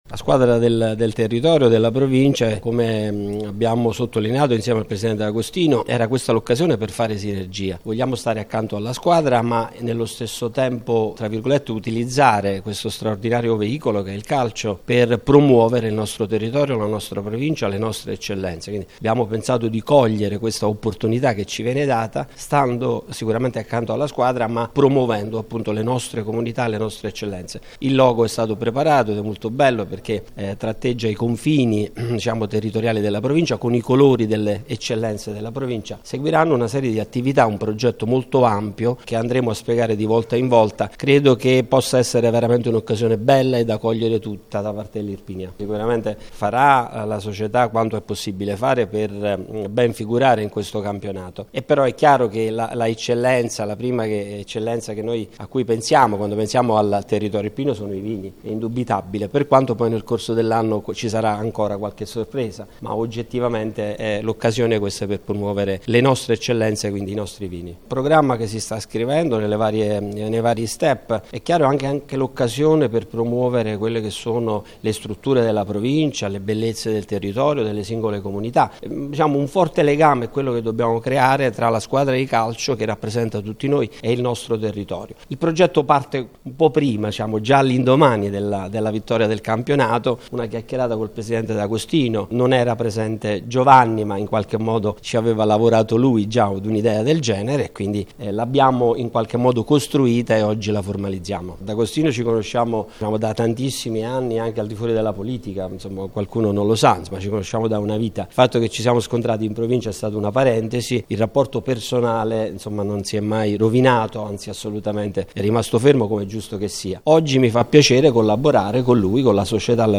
ASCOLTA LE PAROLE DEL PRESIDENTE BUONOPANE